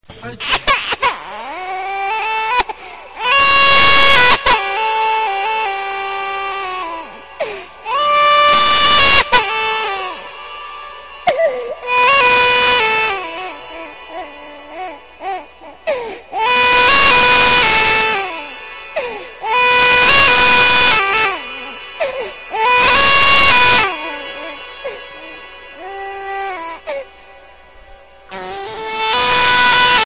File Type : Funny ringtones